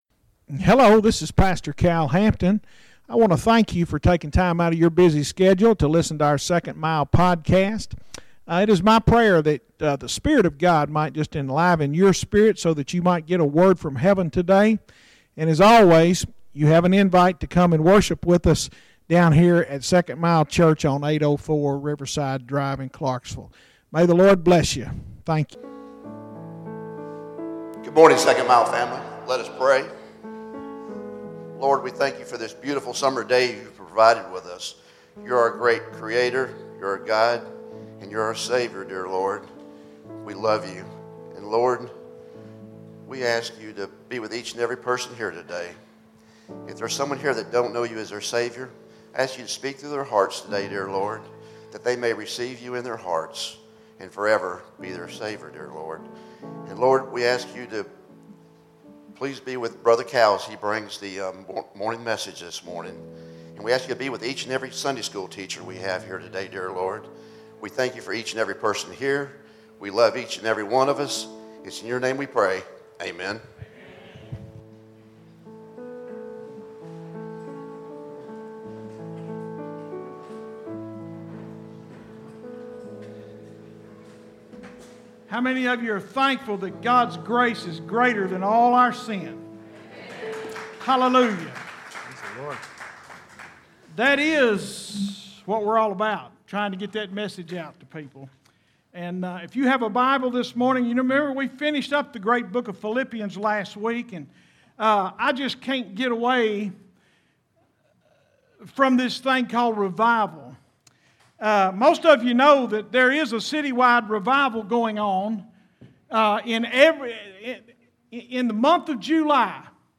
Sermons Archive - Page 2 of 301 - 2nd Mile Church